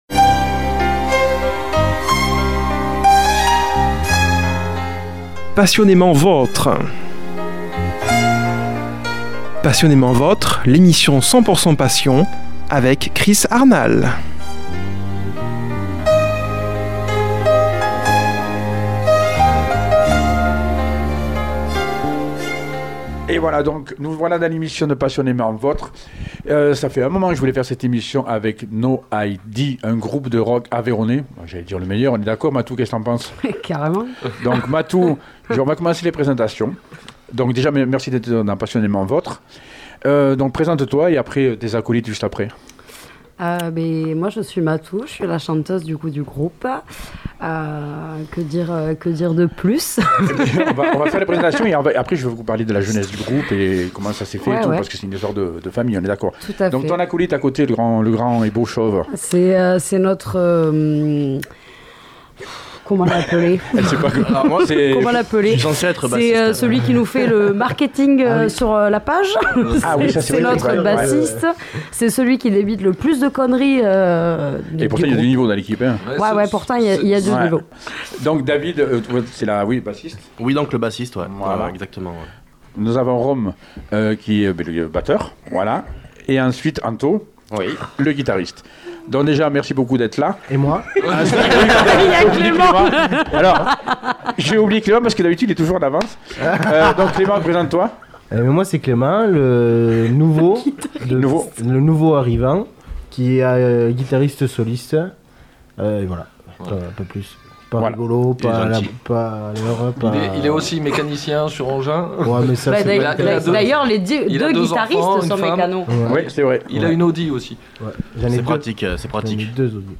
Il a reçu le groupe Aveyronnais NOID. Ambiance survoltée ..fous rires...impro..solo de guitare endiable....Un moment mémorable à écouter et partager